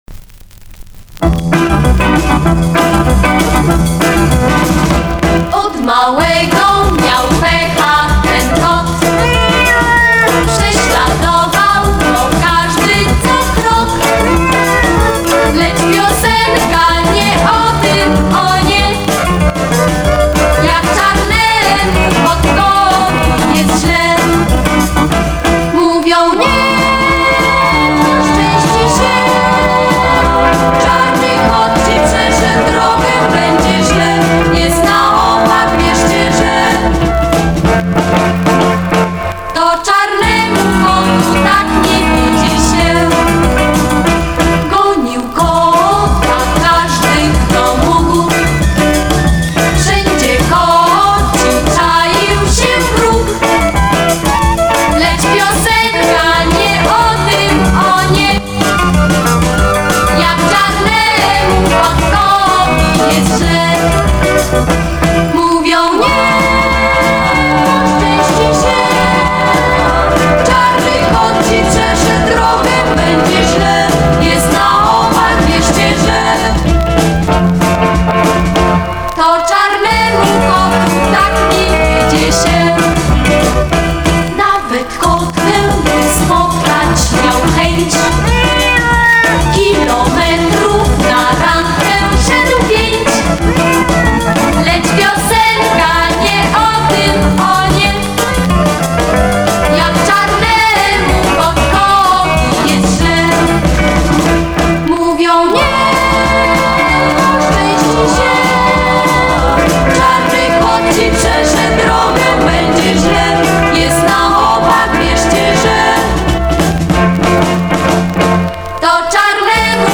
Zenski Zespol Wokalny